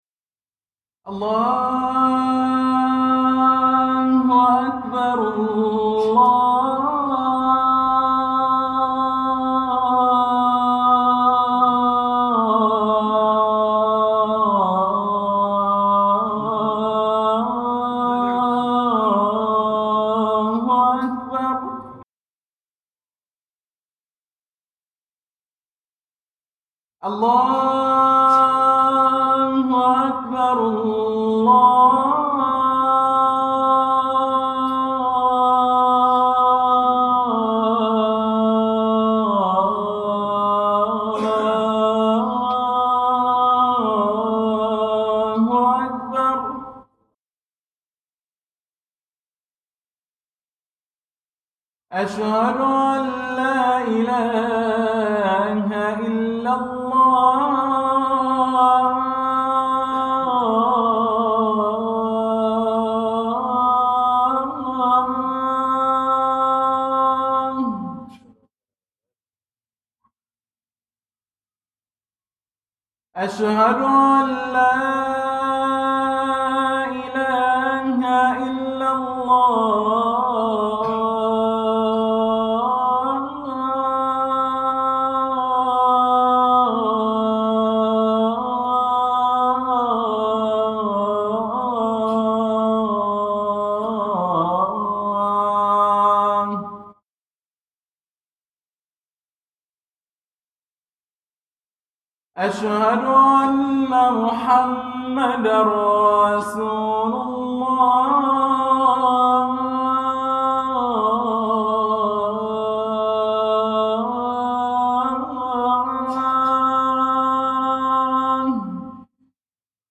أذان
في جامع القلمون الكبير (البحري) لصلاء الجمعة